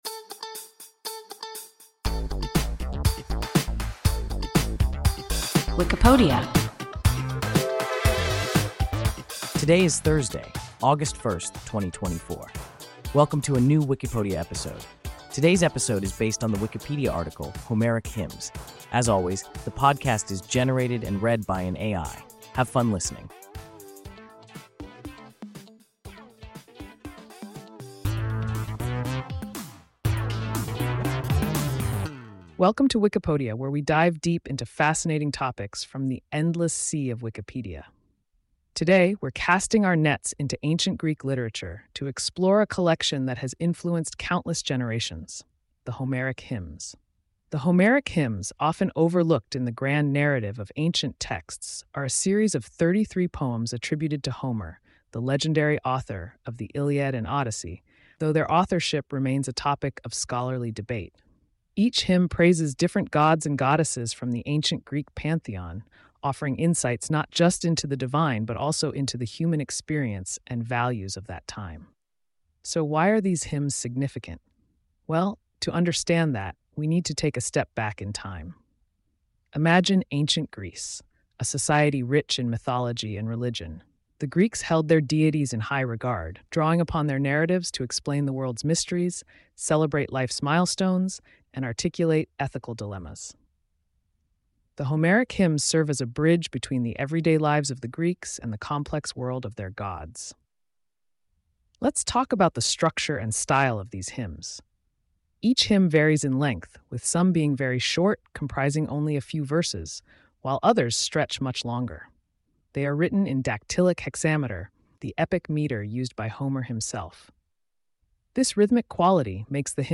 Homeric Hymns – WIKIPODIA – ein KI Podcast